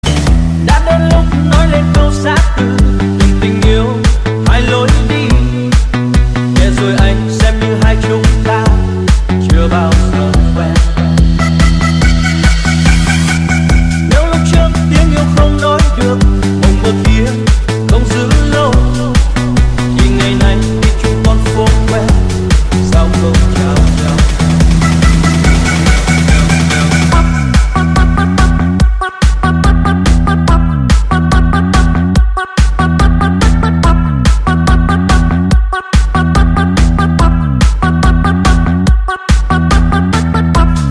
Nhạc Chuông DJ - Nonstop